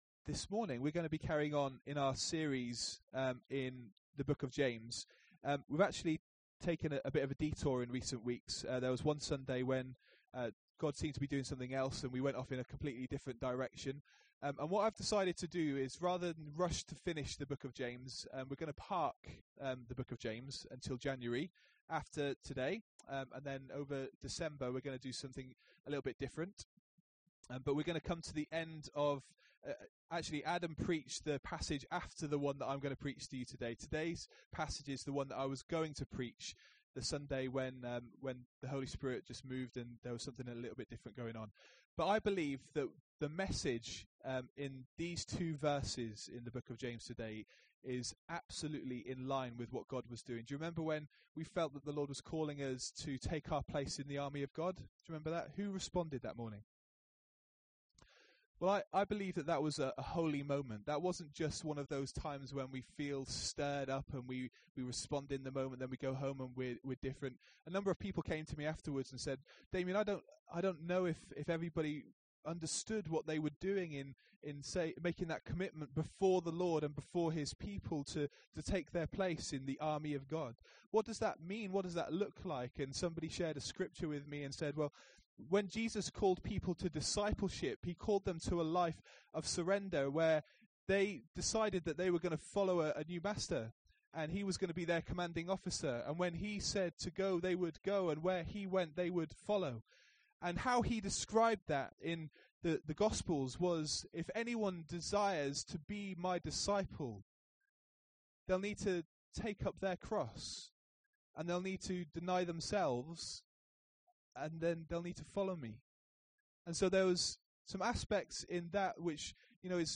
Notes Sermons in this Series In the latest on the series entitled Clarity